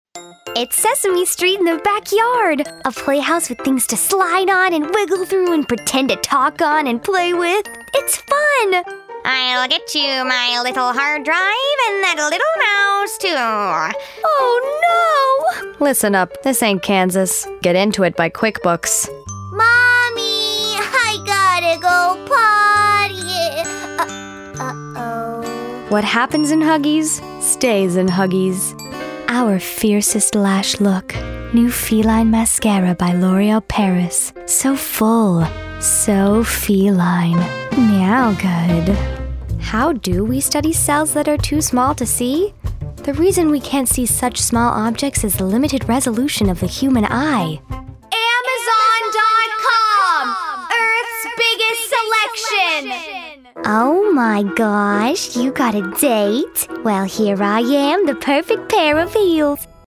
One minute voiceover demo